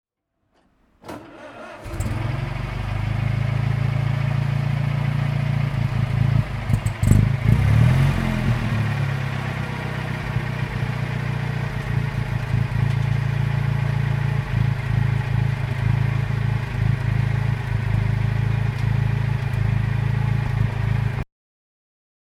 Chenard & Walcker T3 (1924) - Starten und Leerlauf
Chenard_Walcker_1924_korrigiert.mp3